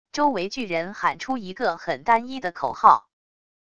周围巨人喊出一个很单一的口号wav音频